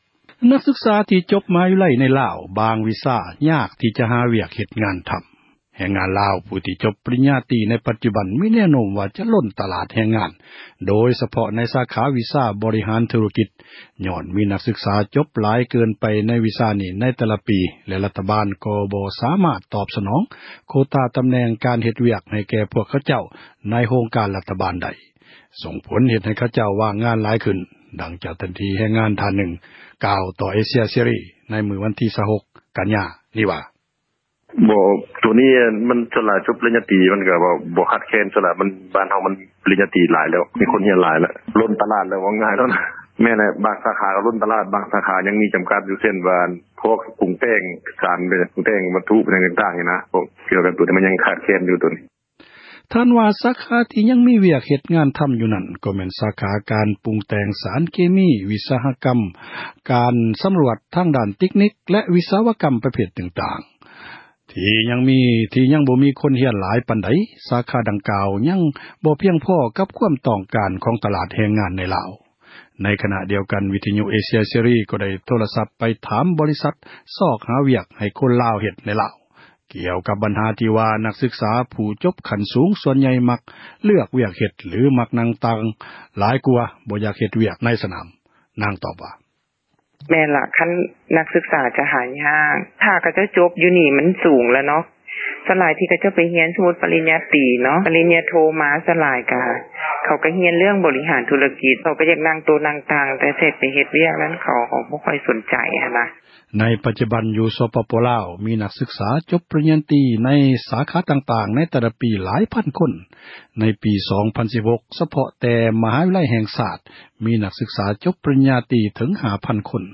ໃນຂນະດຽວກັນ ວິທຍຸເອເຊັຽເສຣີ ກໍໂດ້ ໂທຣະສັບ ໄປຖາມ ບໍຣິສັດ ຊອກຫາວຽກ ໃນລາວ ກ່ຽວກັບ ບັນຫາ ທີ່ວ່າ ນັກສືກສາ ຜູ້ຈົບ ຂັ້ນສູງ ສ່ວນໃຫຍ່ ມັກເລືອກ ວຽກເຮັດ ຫລື ມັກນັ່ງໂຕະ ນັ່ງຕັ່ງ ຫຼາຍກວ່າ ບໍ່ຢາກ ເຮັດວຽກ ໃນພາກສນາມ.